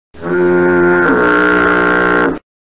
Sponge Stank Noise